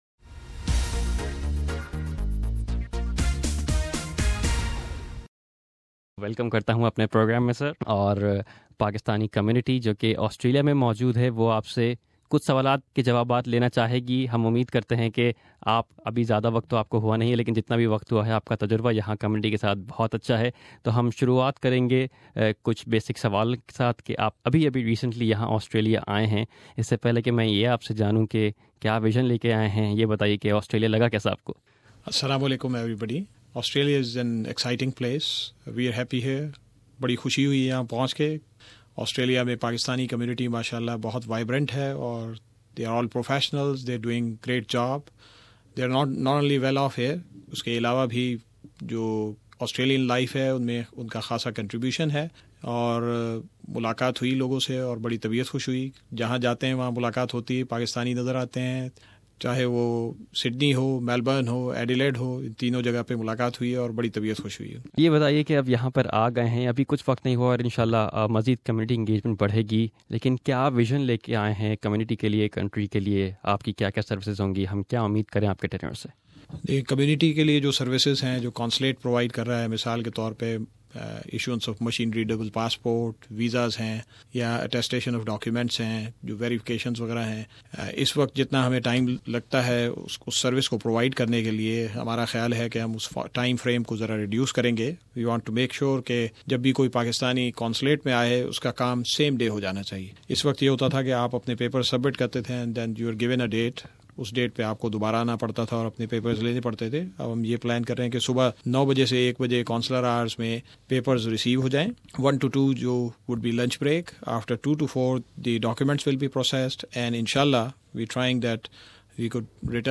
Consul General Abdul Majid Yousfani shares his vision for the community, and experience of meeting Pakistani people in Australia in an interview with SBS Urdu...